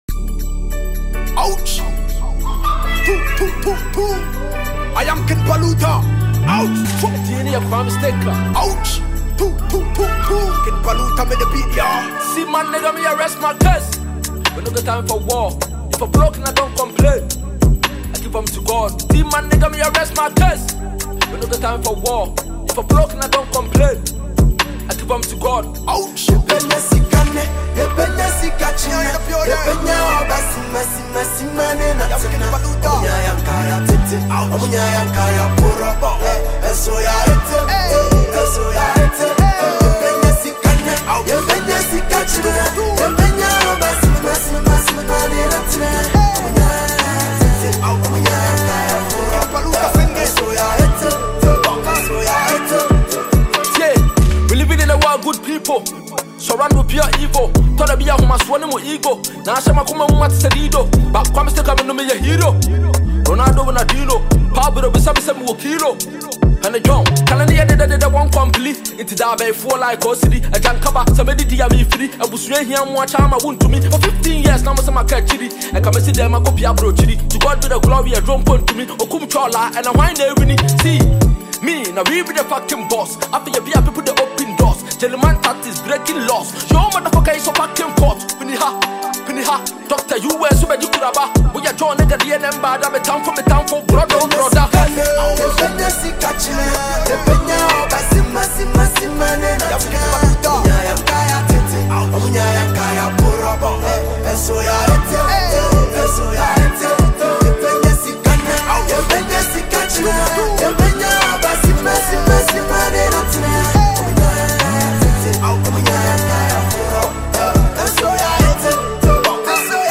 drill record